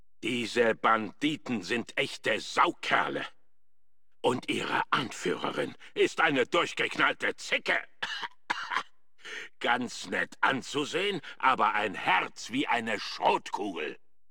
Fallout: Brotherhood of Steel: Audiodialoge
FOBOS-Dialog-Armpit-006.ogg